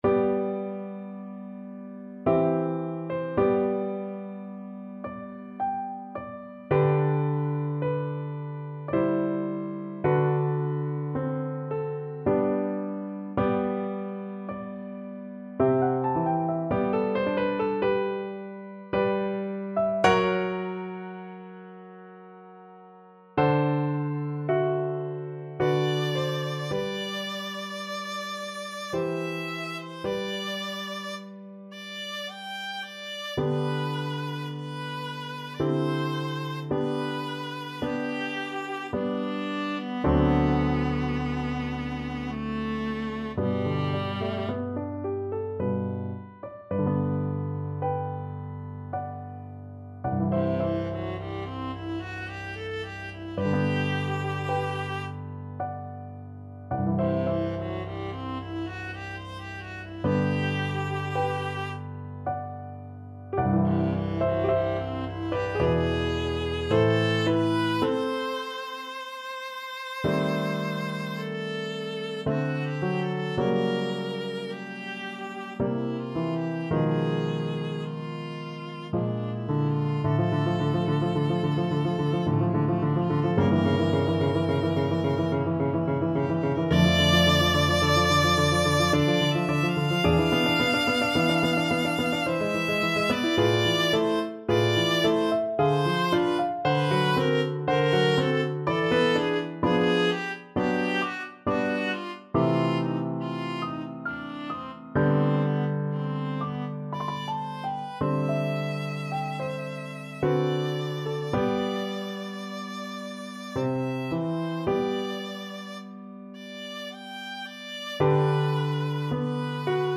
Viola version
Adagio ma non troppo =108
3/4 (View more 3/4 Music)
Classical (View more Classical Viola Music)